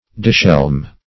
Search Result for " dishelm" : The Collaborative International Dictionary of English v.0.48: dishelm \dis*helm"\ (d[i^]s*h[e^]lm"), v. t. [Pref. dis- + helm helmet.] To deprive of the helmet.